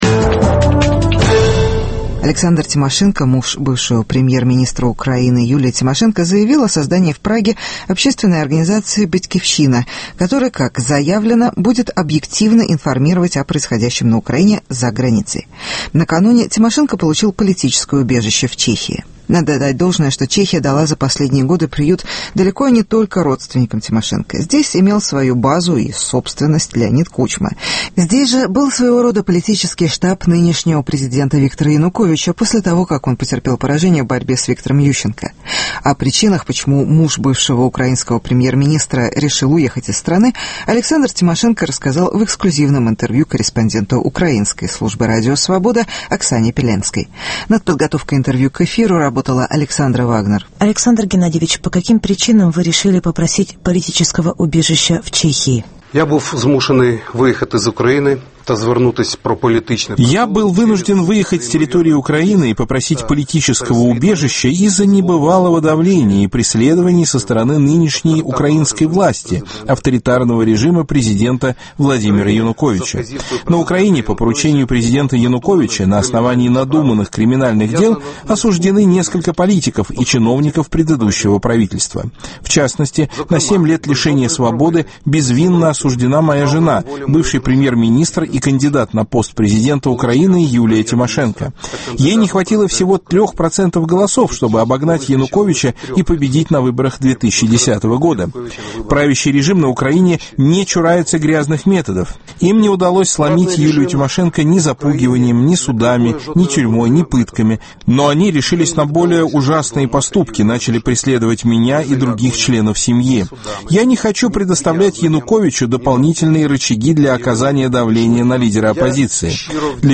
Задачи украинской оппозиции в Чехии. Интервью